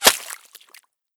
water01gr.ogg